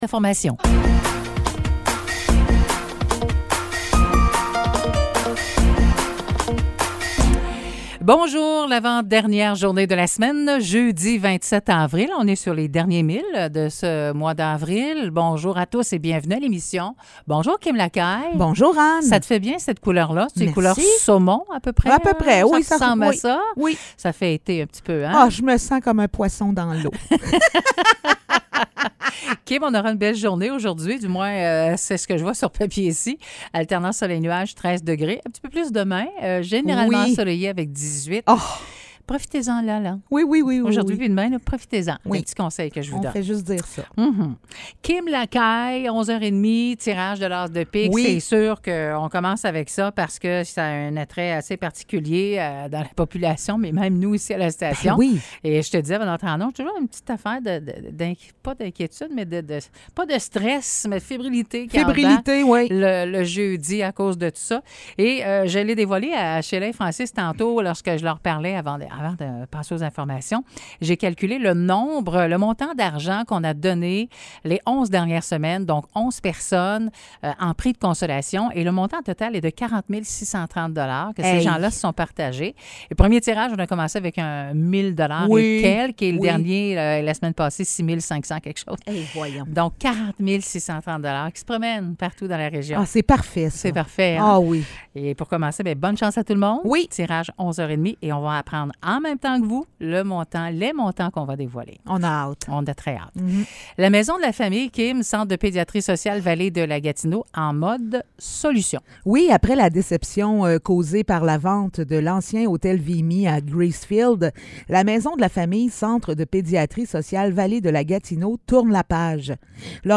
Nouvelles locales - 27 avril 2023 - 9 h